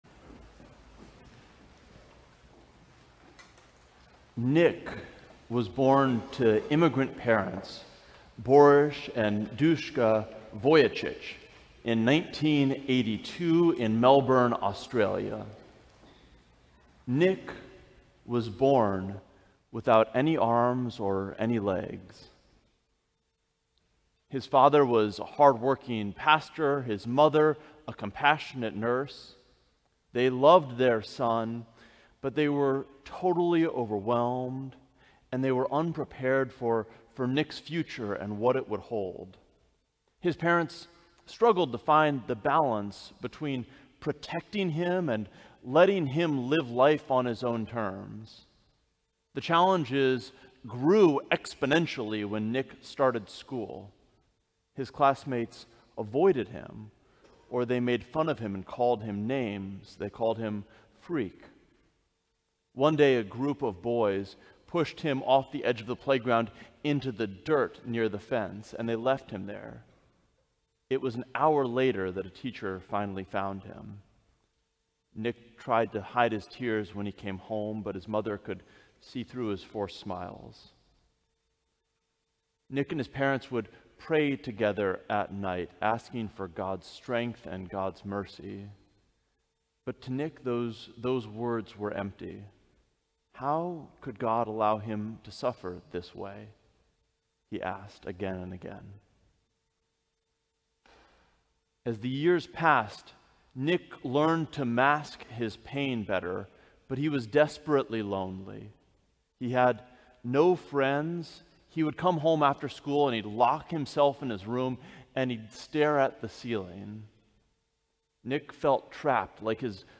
Sermon: When you're suffering - St. John's Cathedral
The Twenty-Third Sunday after Pentecost, October 27, 2024
Sermons from St. John's Cathedral Sermon: When you're suffering Play Episode Pause Episode Mute/Unmute Episode Rewind 10 Seconds 1x Fast Forward 30 seconds 00:00 / 00:15:46 Subscribe Share Apple Podcasts RSS Feed Share Link Embed